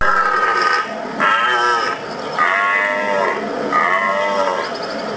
nel nostro appennino sono in piena attività
è questa è la sua voce
Ancora cervi bramito.wav